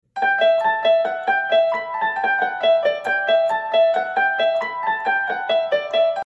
Piano Tutorial